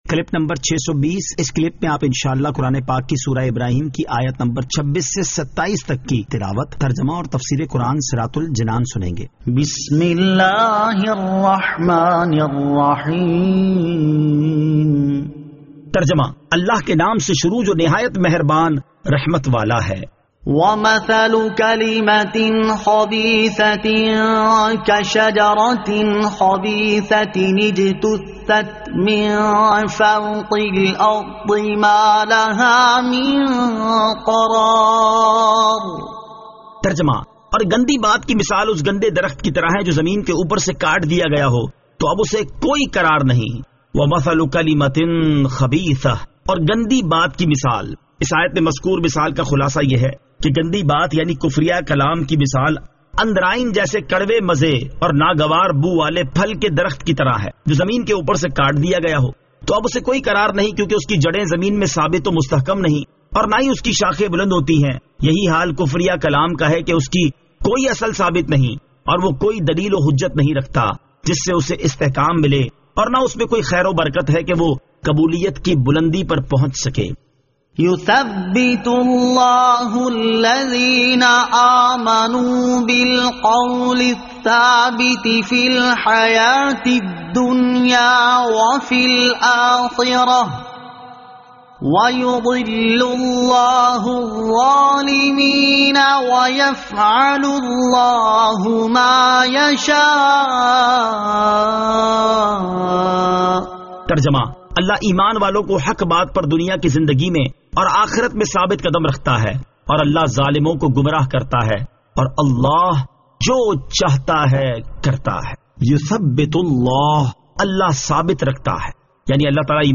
Surah Ibrahim Ayat 26 To 27 Tilawat , Tarjama , Tafseer